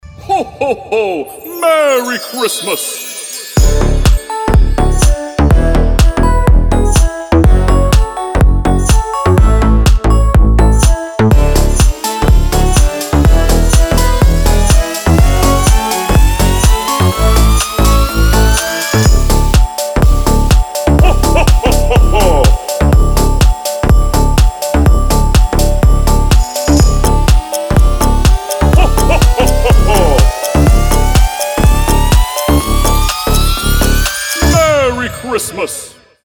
мелодичные
Club House
добрые
колокольчики
смех
рождественские
Добрый рождественский клубняк на звоночек